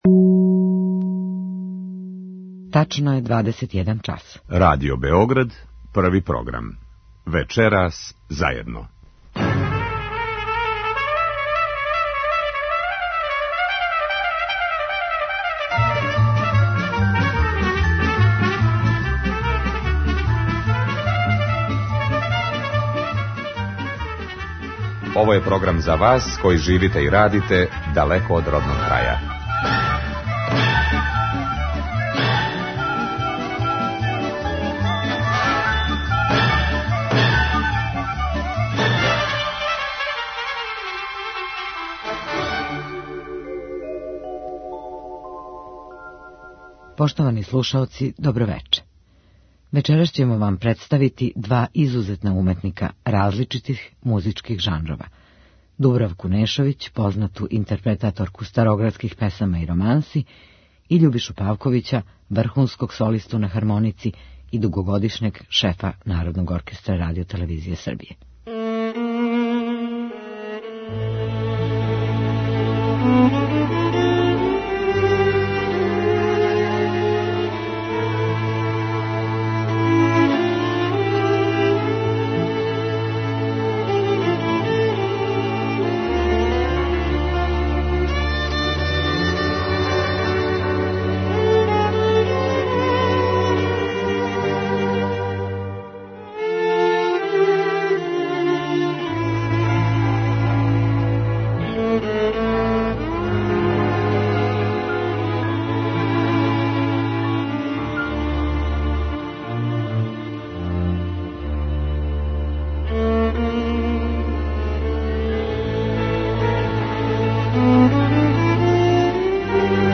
староградских песама и романси
солиста на хармоници